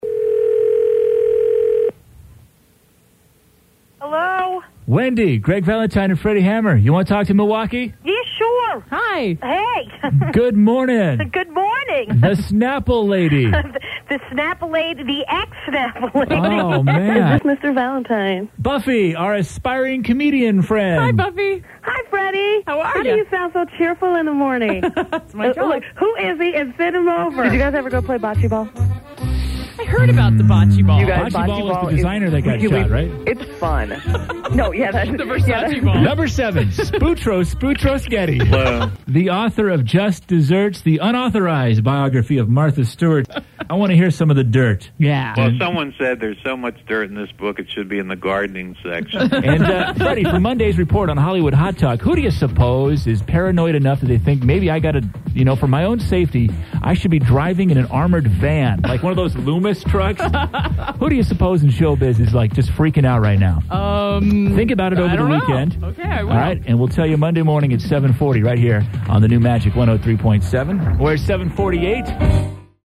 MORNING SHOW AIRCHECK